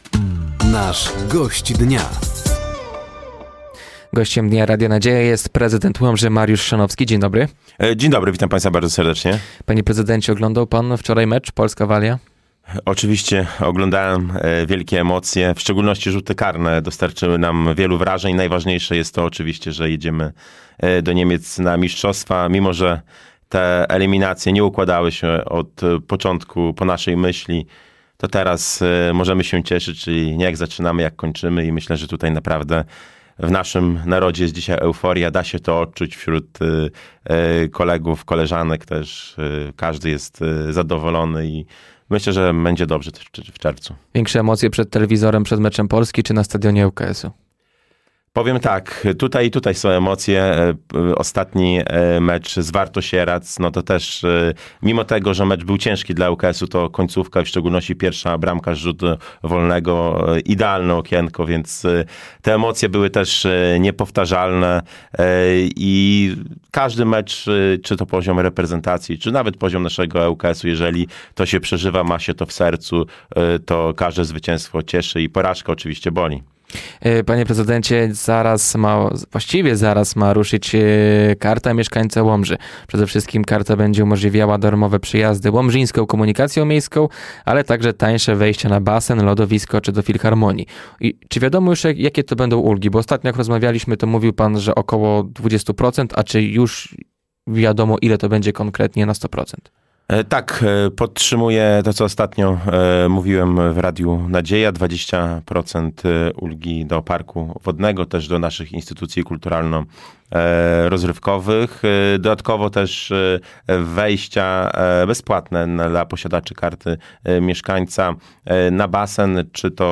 Gościem Dnia Radia Nadzieja był prezydent Łomży Mariusz Chrzanowski. Tematem rozmowy była Karta Mieszkańca Łomży oraz aplikacja „Zasmakuj w Łomży”.